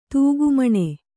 ♪ tūgu mane